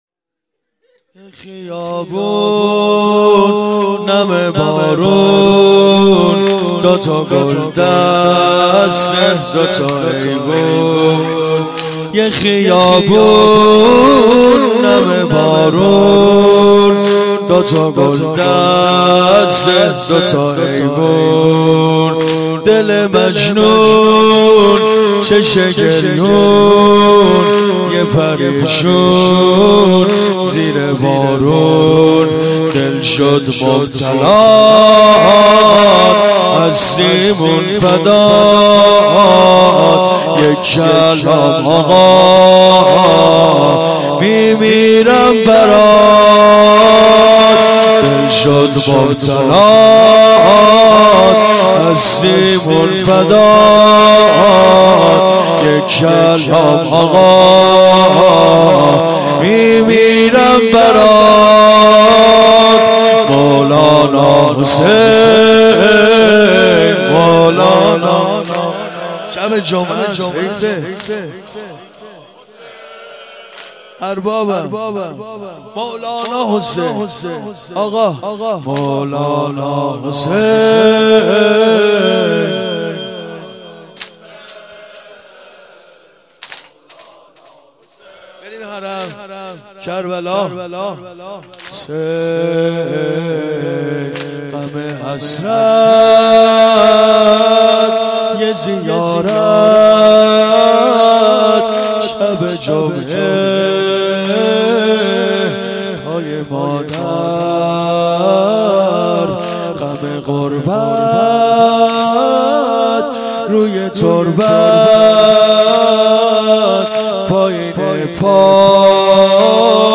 شب دوم